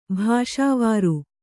♪ bhāṣāvāru